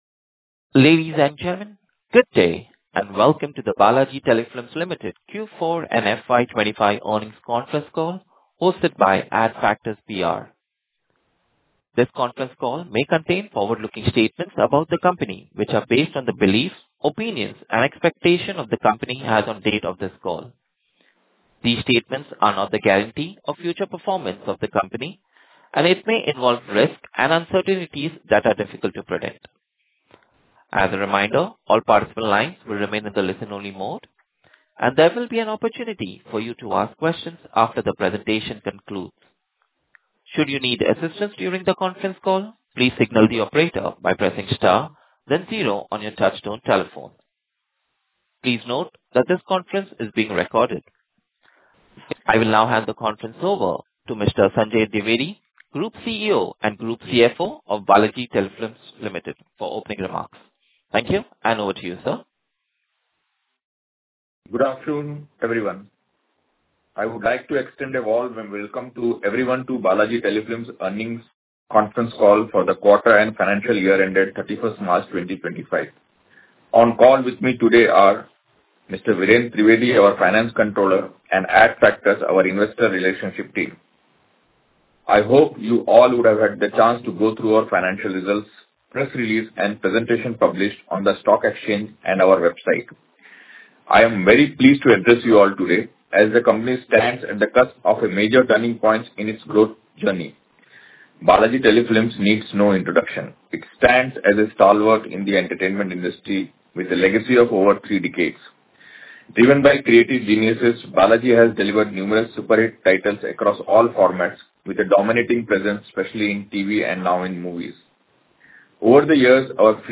BTL_Q4 FY25 Earnings Call Audio.mp3